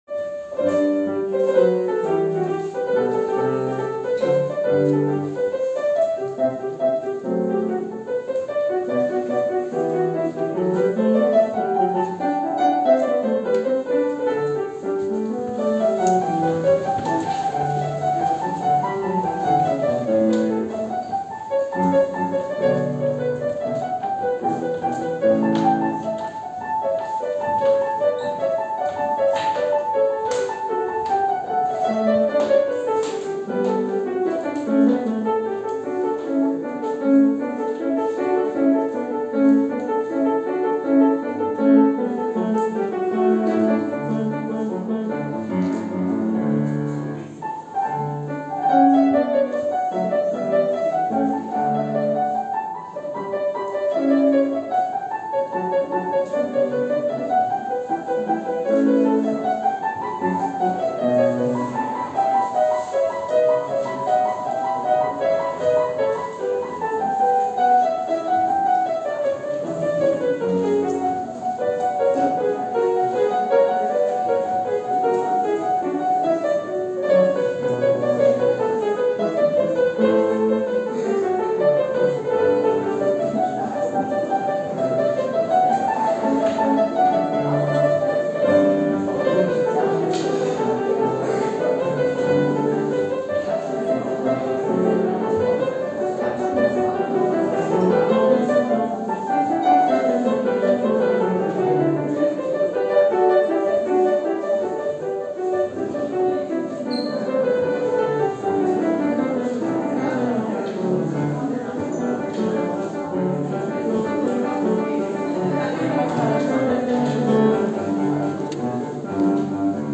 Orgelvorspiel